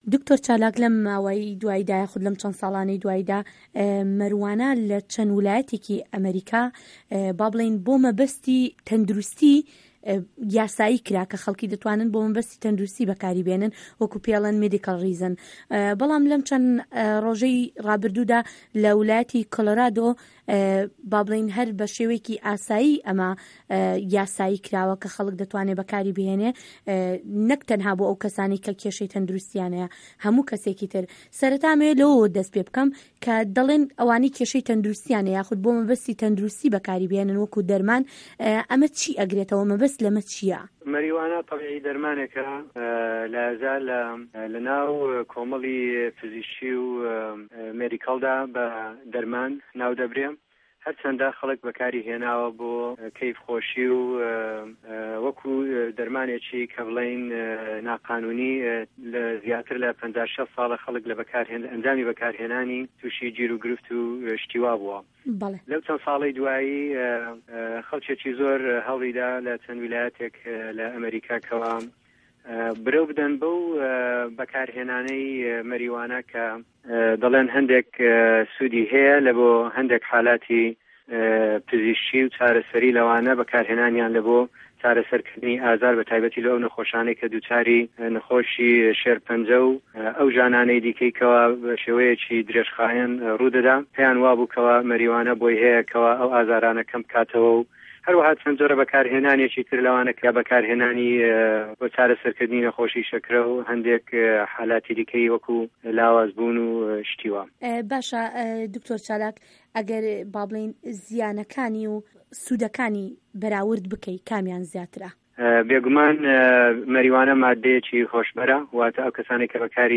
ده‌قی وتووێژه‌که‌.